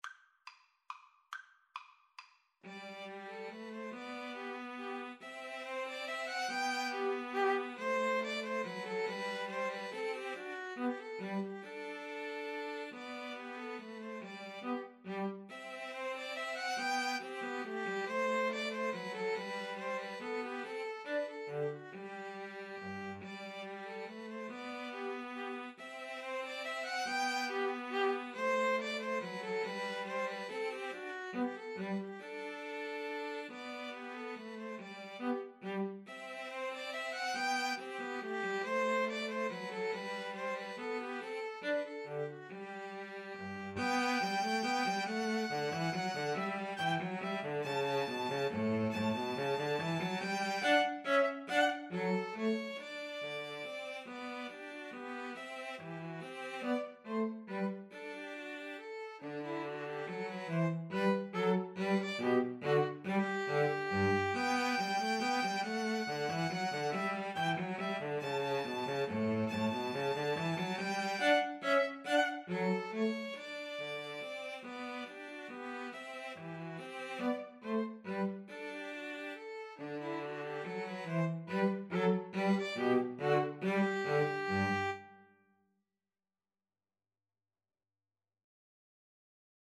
ViolinCelloPianoPiano
3/4 (View more 3/4 Music)
Molto allegro = c. 140
Classical (View more Classical Piano Trio Music)